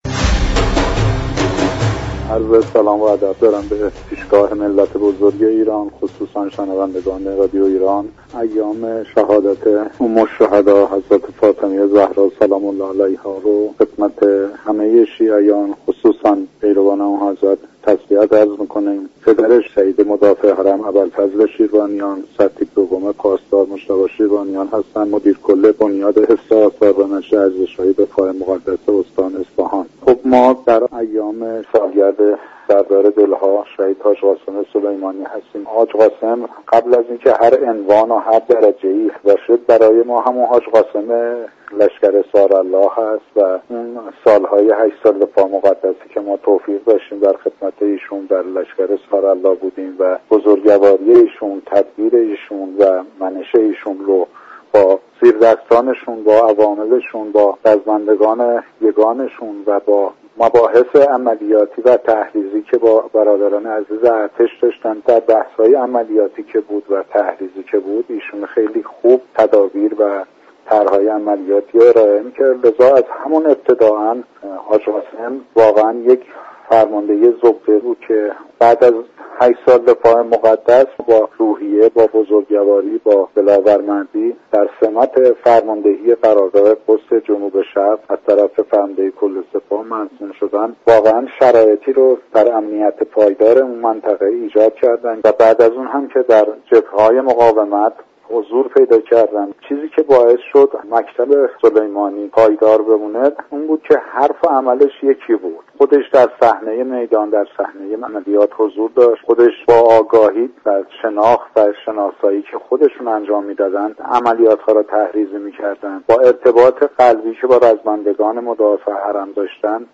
برنامه پلاك هشت امروز به مناسبت سالگرد شهادت حاج قاسم سلیمانی از ساعت 12:00 تا 13:55 به شكلی ویژه از رادیو ایران پخش شد.